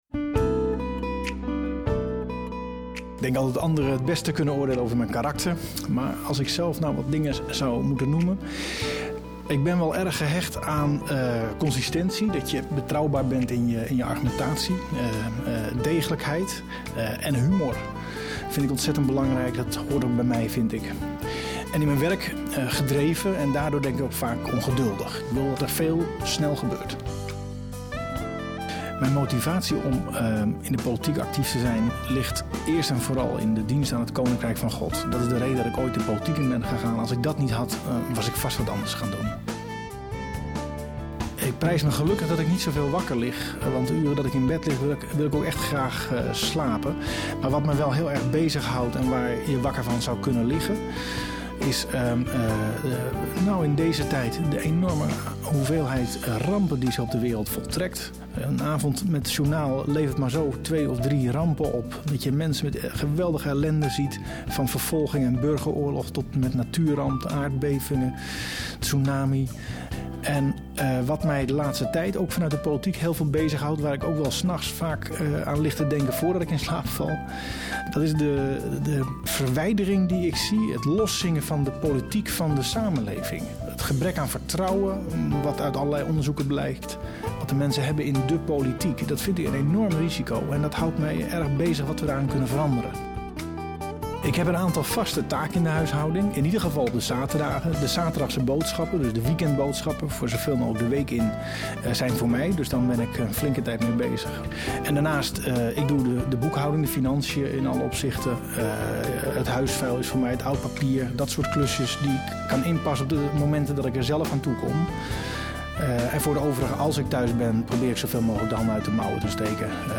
In deze radio uitzending hoort u de top 10 van de kandidatenlijst van de ChristenUnie over hun speerpunt voor de verkiezingen.